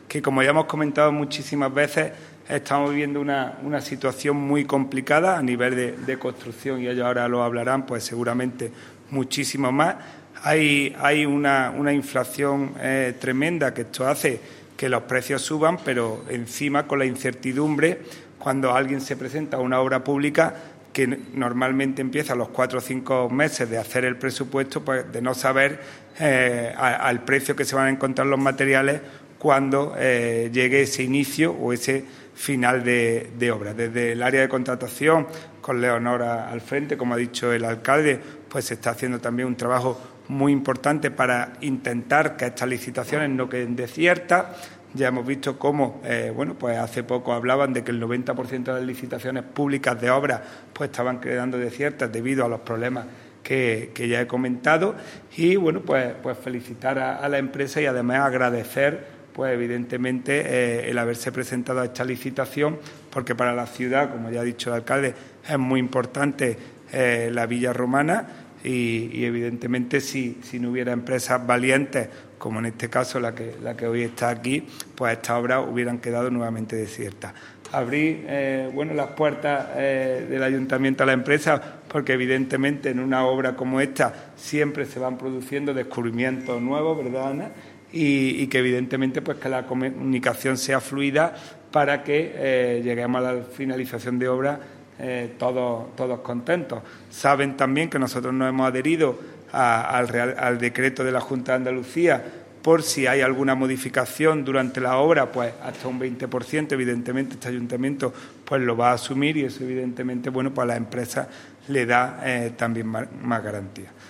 El alcalde de Antequera, Manolo Barón, el teniente de alcalde delegado de Contratación, Juan Rosas, y la teniente de alcalde de Patrimonio Histórico, Ana Cebrián, han comparecido hoy en rueda de prensa para anunciar la inminente continuación de las obras de recuperación y rehabilitación de la Villa Romana de la Estación, considerada uno de los principales vestigios arqueológicos de época romana en Andalucía.
Cortes de voz